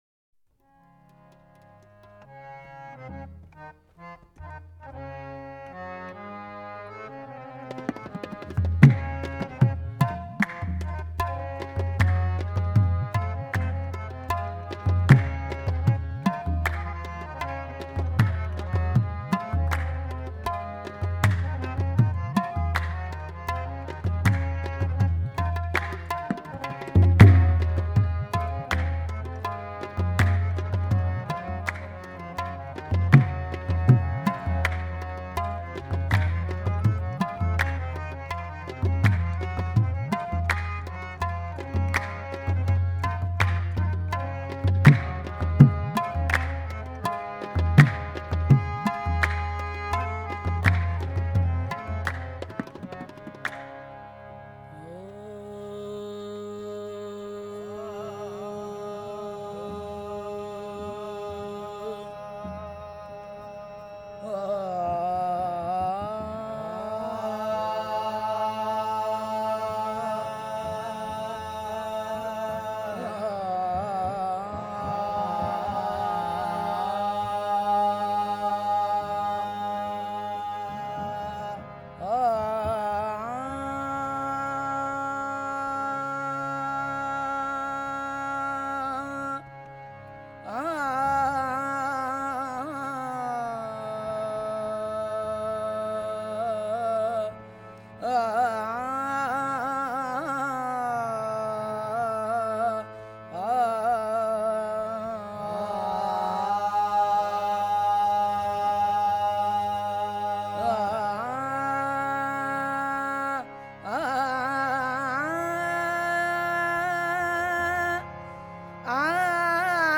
Sufi Songs